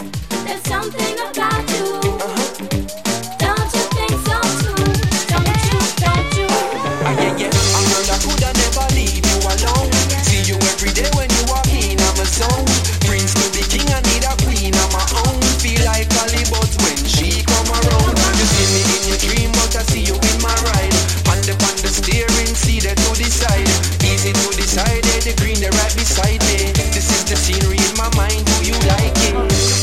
TOP >Vinyl >Drum & Bass / Jungle
TOP > Vocal Track
TOP > Jump Up / Drum Step